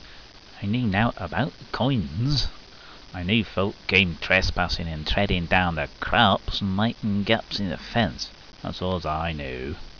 coins.wav